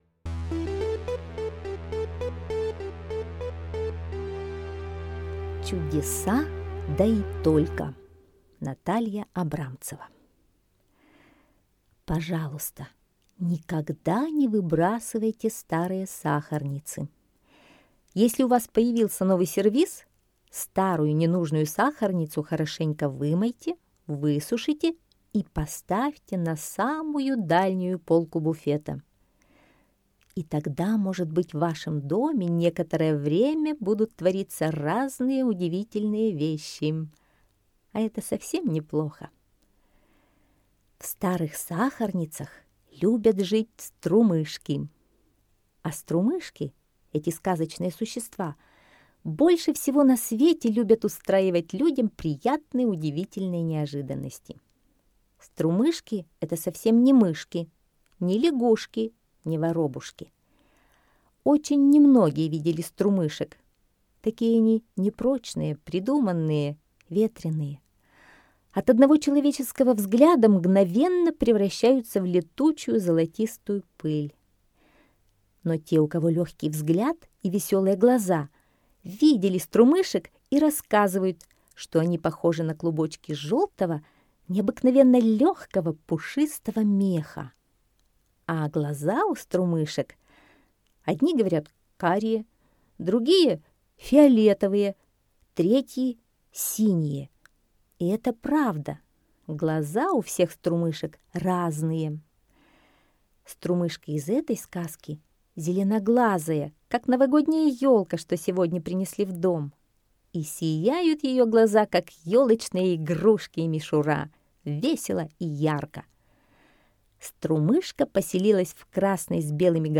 Аудиосказка «Чудеса да и только»